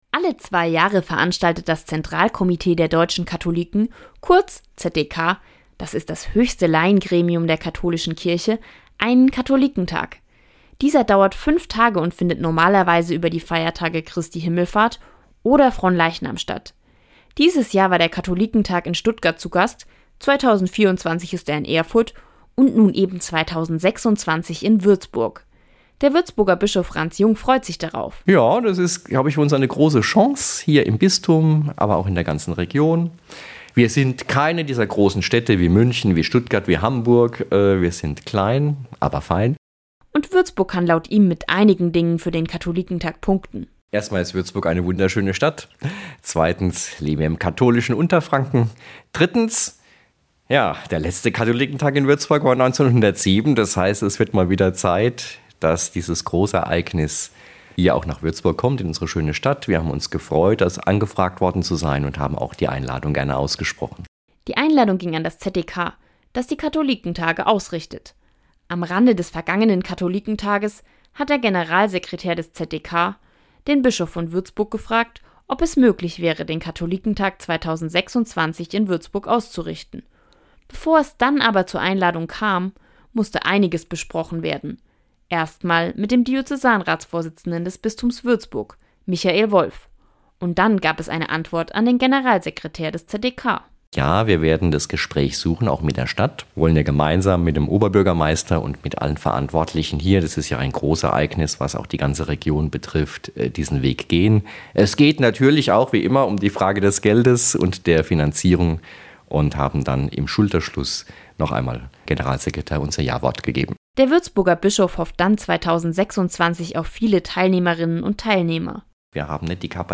hat mit dem Würzburger Bischof darüber